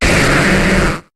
Cri de Volcaropod dans Pokémon HOME.